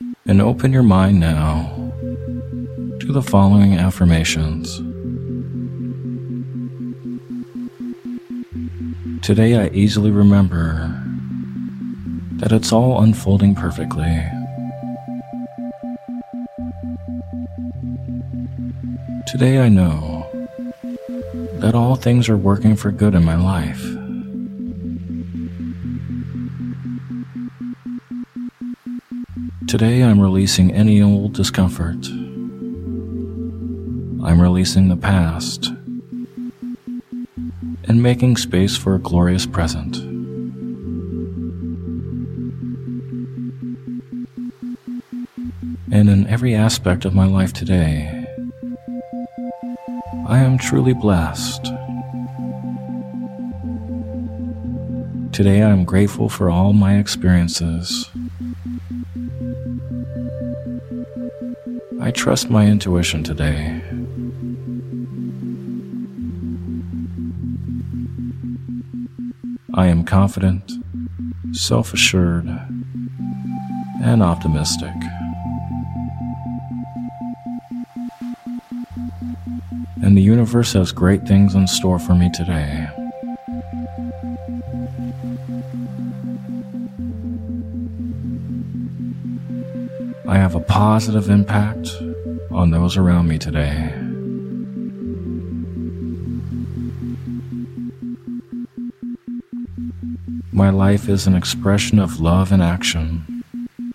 - Power Meditation With Isochronic Tones quantity + Add to cart Categories: Guided Meditations With Isochronic Tones , Hypnotic Labs Exclusive Meditations